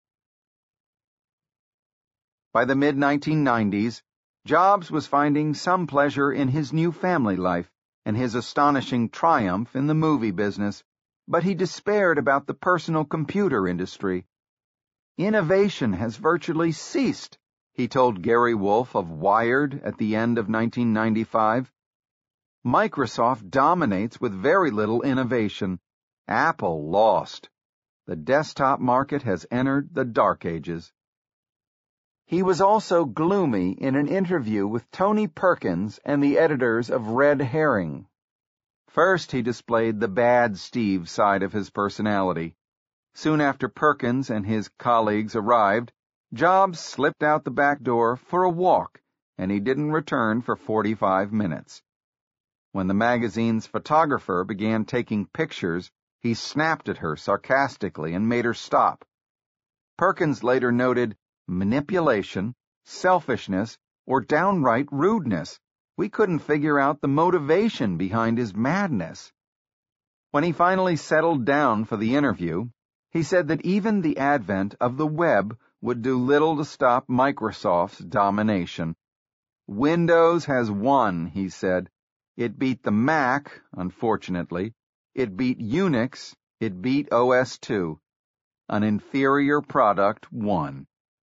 在线英语听力室乔布斯传 第347期:万物解体(3)的听力文件下载,《乔布斯传》双语有声读物栏目，通过英语音频MP3和中英双语字幕，来帮助英语学习者提高英语听说能力。
本栏目纯正的英语发音，以及完整的传记内容，详细描述了乔布斯的一生，是学习英语的必备材料。